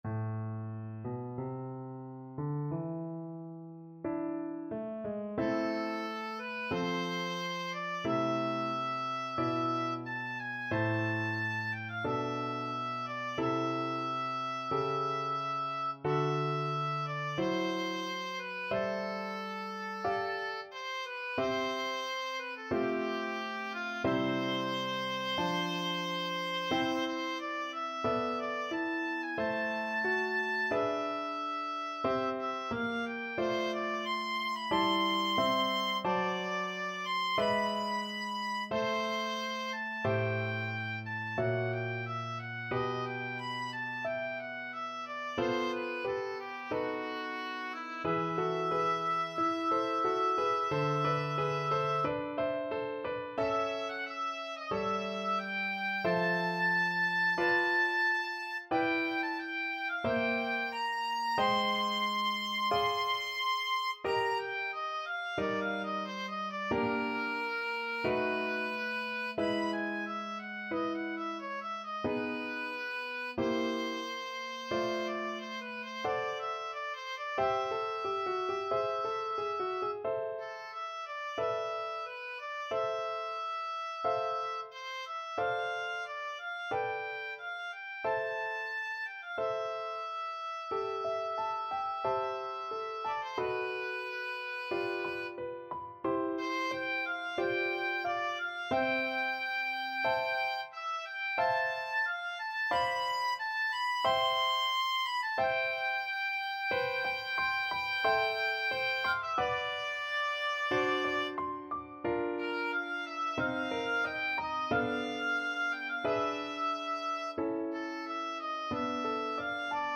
A minor (Sounding Pitch) (View more A minor Music for Oboe )
4/4 (View more 4/4 Music)
Adagio, molto tranquillo (=60) =45
Classical (View more Classical Oboe Music)